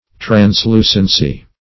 Translucence \Trans*lu"cence\, Translucency \Trans*lu"cen*cy\,